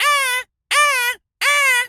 pgs/Assets/Audio/Animal_Impersonations/seagul_squawk_seq_05.wav at master
seagul_squawk_seq_05.wav